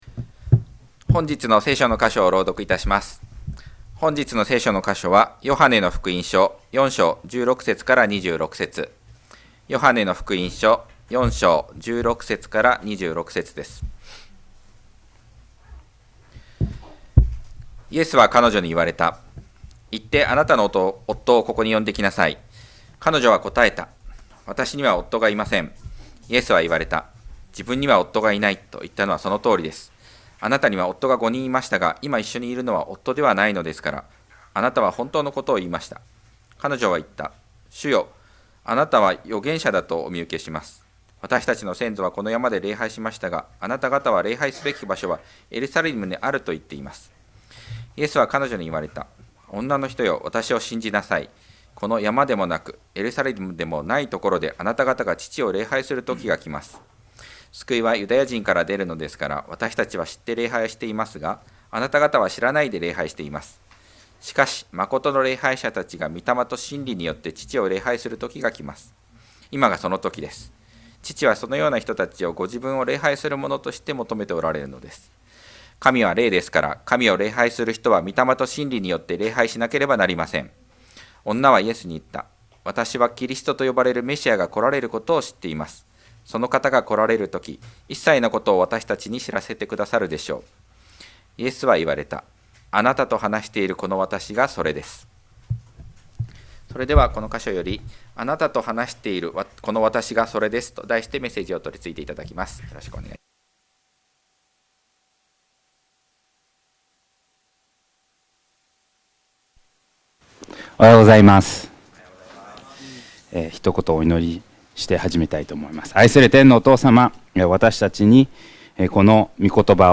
2025年2月16日礼拝 説教 「あなたと話しているこのわたしがそれです」 – 海浜幕張めぐみ教会 – Kaihin Makuhari Grace Church